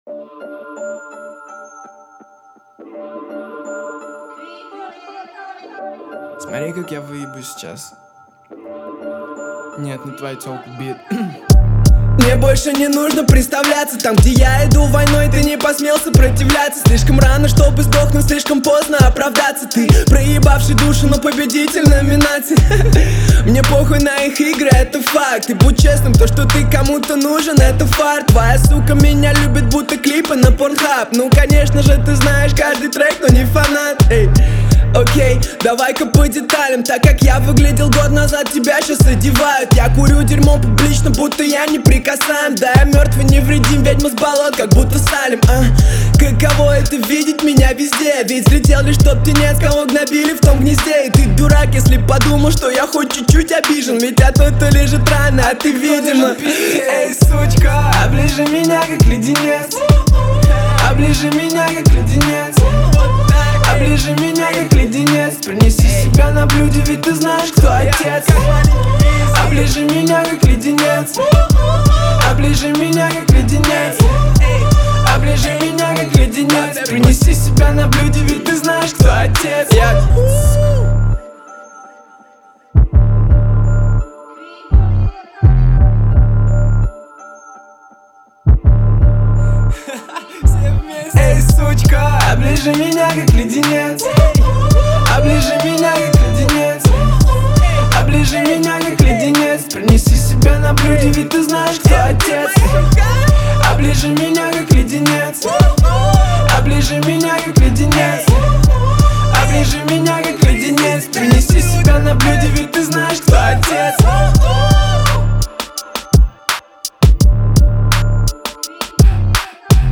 Bass
Cloud Rap
Пошленький молодежный рэпчик)